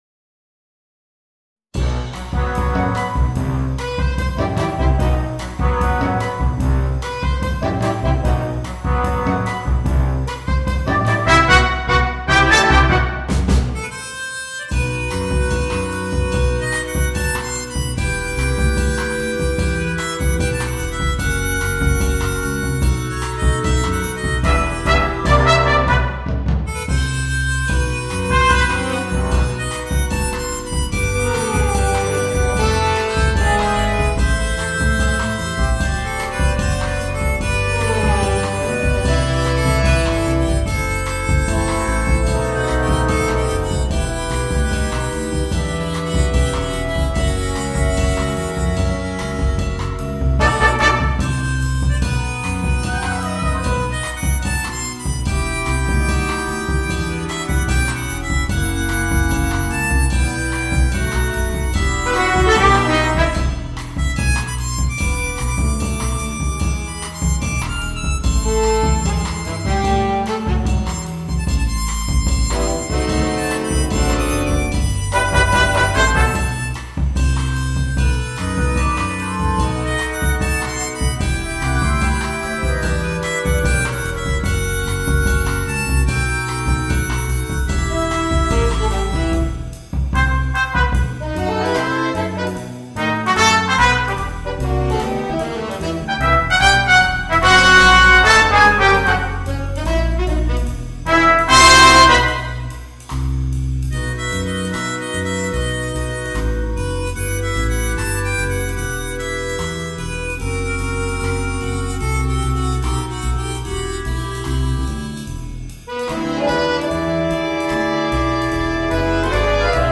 для духового оркестра.